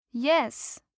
알림음 8_WoodDoorKnockKnock2.mp3